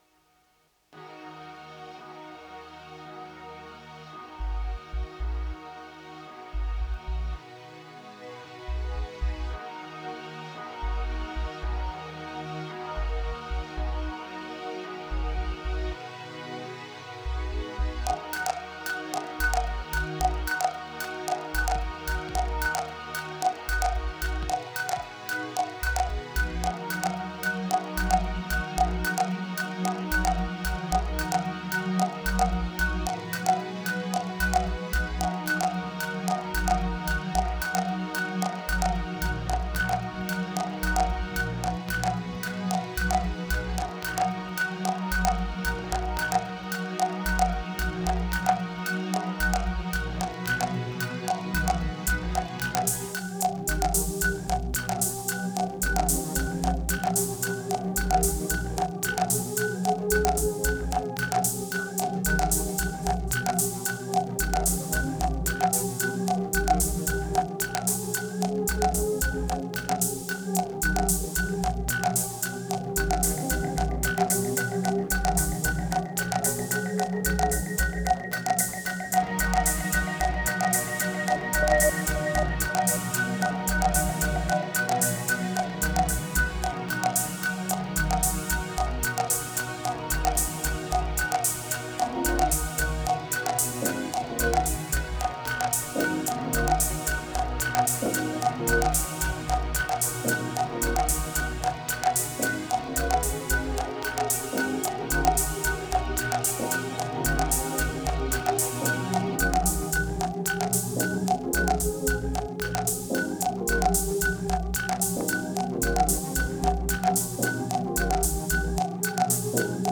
2831📈 - 95%🤔 - 112BPM🔊 - 2017-09-10📅 - 1442🌟